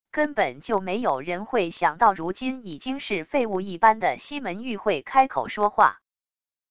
根本就没有人会想到如今已经是废物一般的西门玉会开口说话wav音频生成系统WAV Audio Player